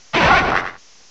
The cries from Chespin to Calyrex are now inserted as compressed cries
sirfetchd.aif